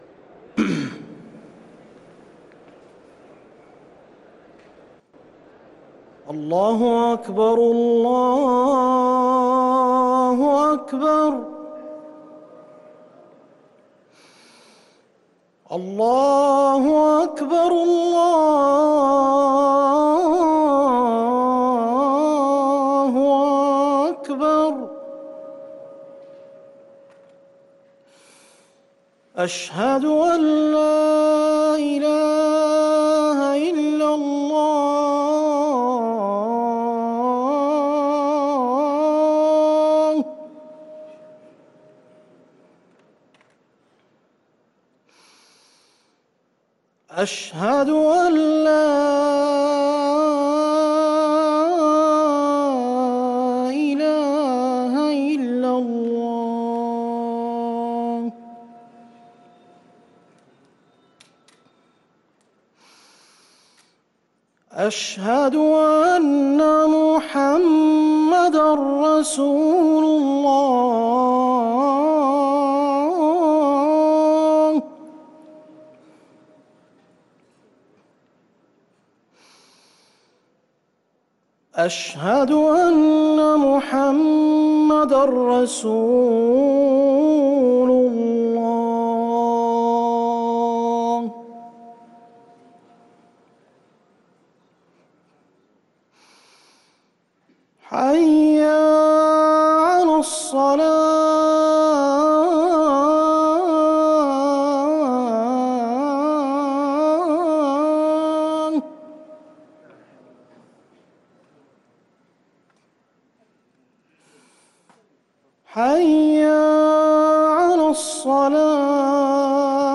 أذان الفجر للمؤذن عبدالمجيد السريحي الأربعاء 10 ذو الحجة 1444هـ > ١٤٤٤ 🕌 > ركن الأذان 🕌 > المزيد - تلاوات الحرمين